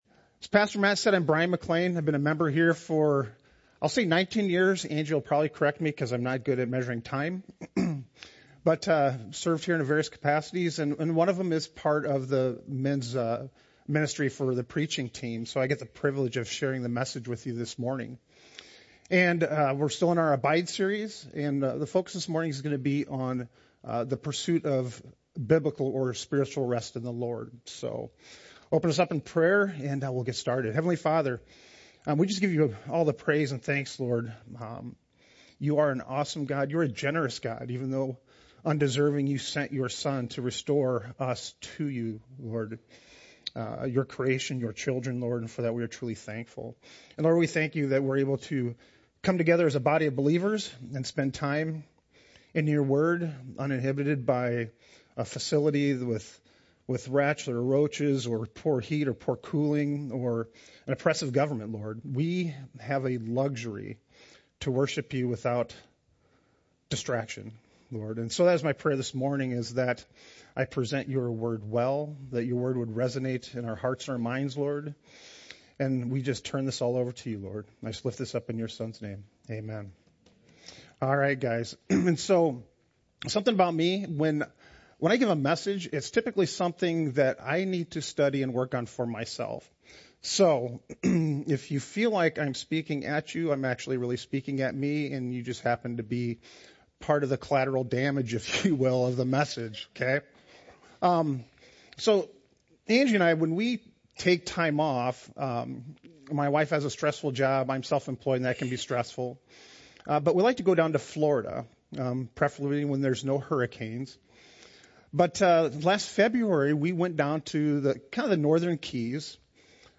Oak Grove Church Sermons